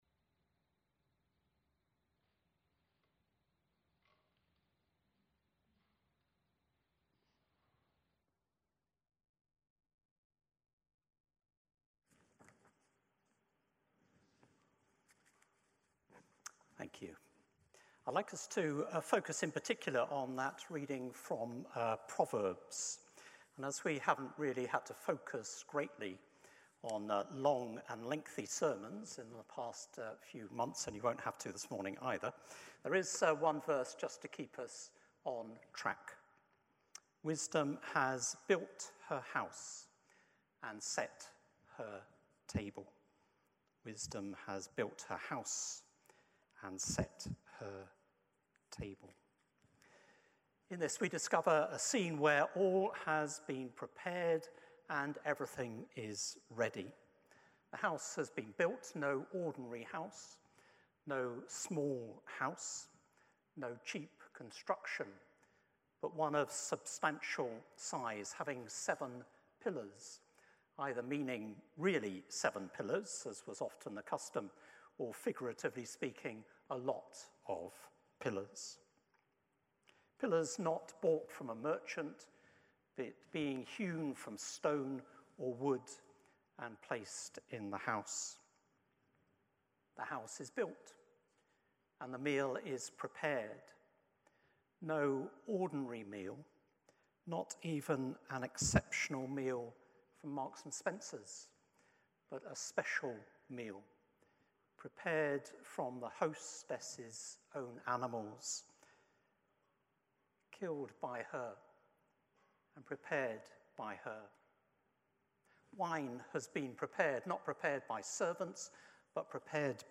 A collection of Podcasts from Wesley Memorial Church preachers.